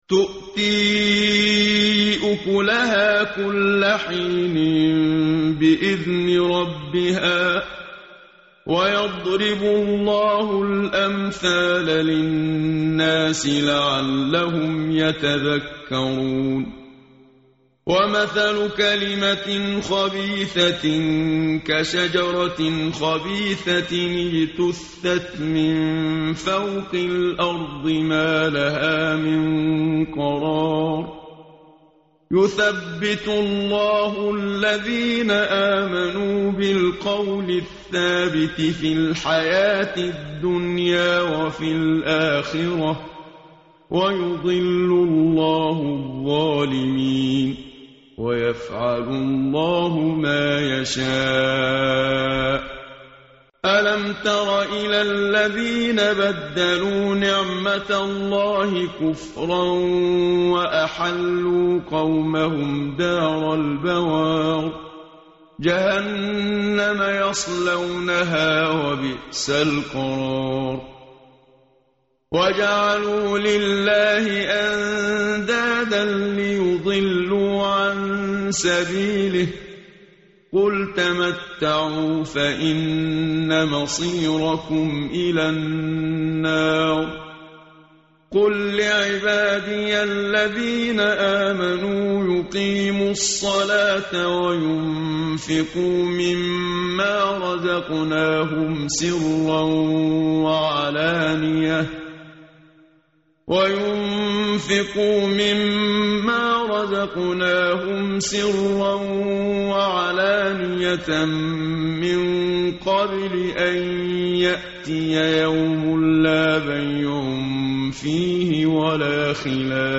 متن قرآن همراه باتلاوت قرآن و ترجمه
tartil_menshavi_page_259.mp3